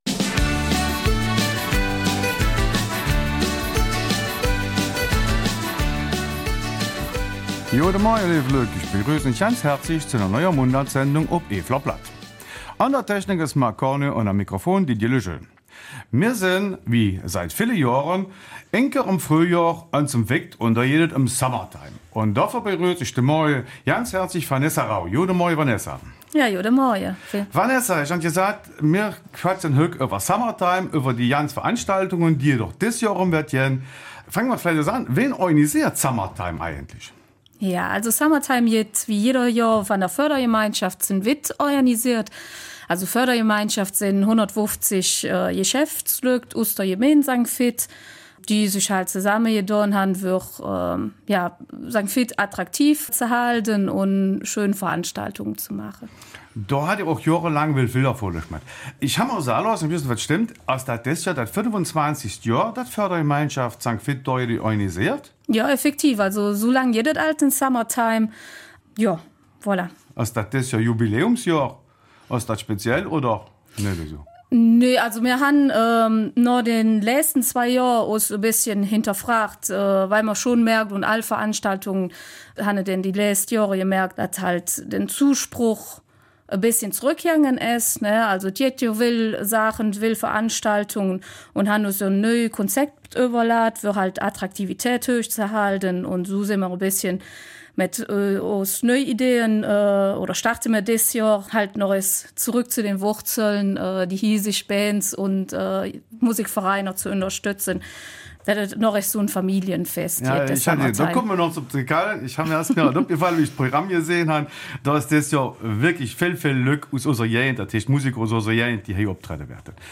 Eifeler Mundart: Summertime St. Vith 2025